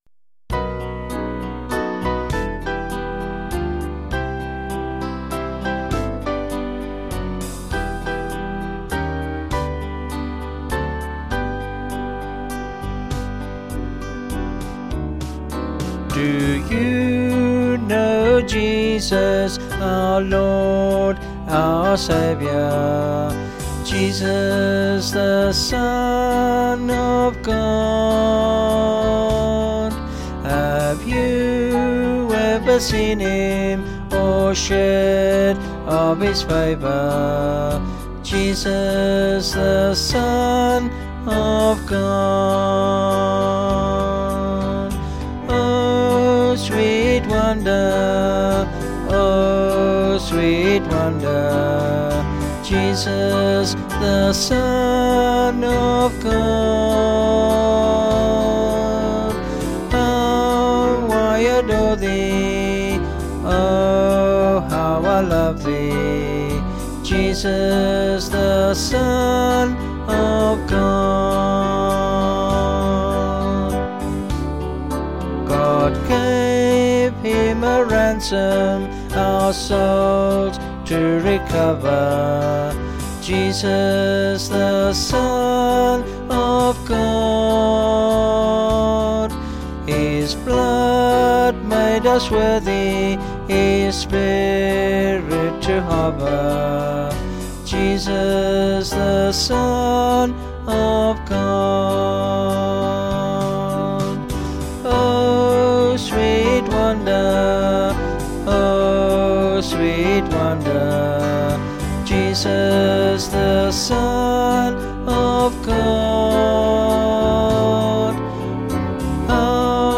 Vocals and Band   265.7kb Sung Lyrics